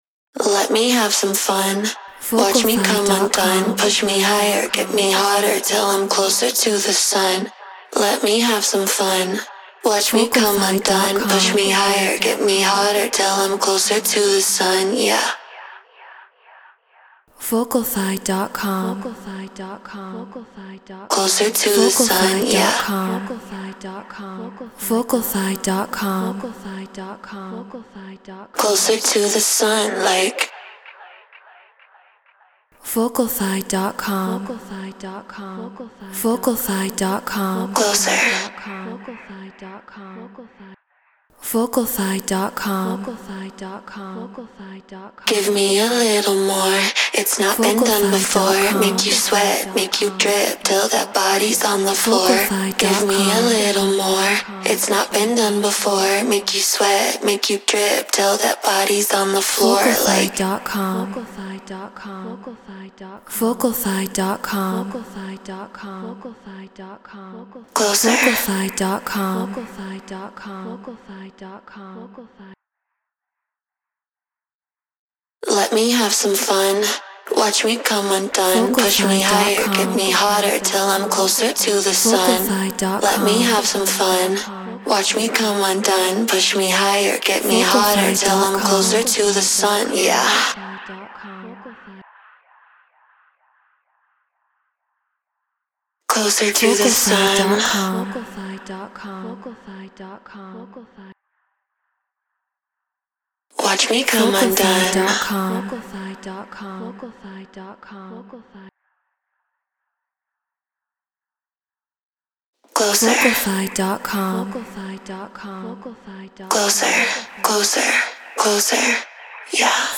Tech House 128 BPM Fmin
Shure SM7B Scarlett 2i2 4th Gen Ableton Live Treated Room